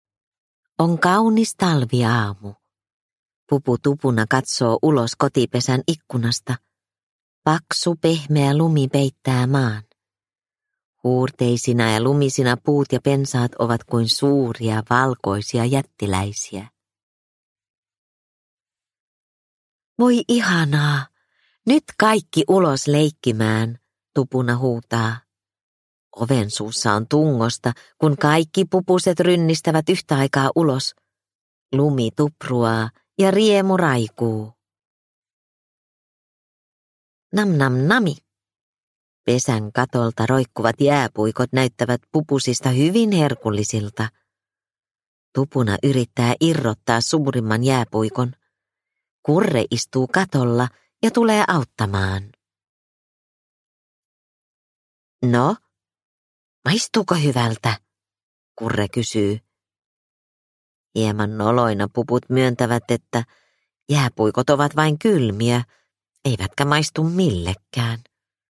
Pupu Tupuna ja ystävät – Ljudbok – Laddas ner